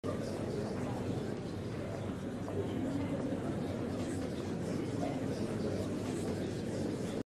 Crowd Murmur Loop Bouton sonore